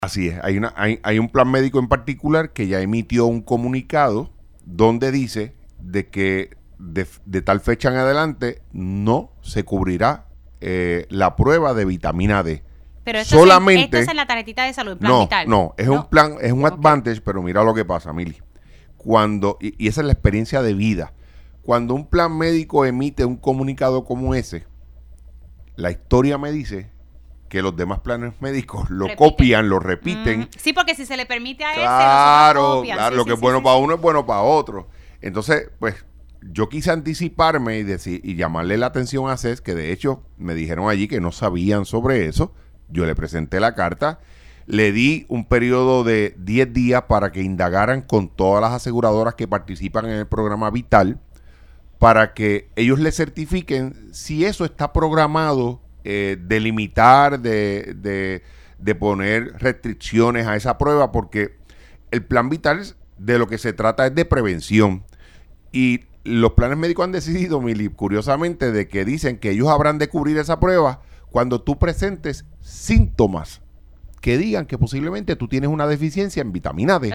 El senador Juan Oscar Morales informó en Pega’os en la Mañana que la Comisión de Salud le dió 10 días a la Administración de Seguros de Salud (ASES) para indagar sobre cuales seguros médicos han eliminado las pruebas de vitamina D de la cobertura de sus beneficiarios.
302-JUAN-OSCAR-MORALES-SENADOR-PNP-LE-DA-10-DIAS-A-ASES-PARA-QUE-INDAGUE-SOBRE-ELIMINACION-DE-A-DE-VITAMINA-D.mp3